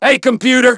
synthetic-wakewords
ovos-tts-plugin-deepponies_Trevor_en.wav